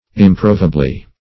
-- Im*pro"a*ble*ness, n. -- Im*prov"a*bly, adv.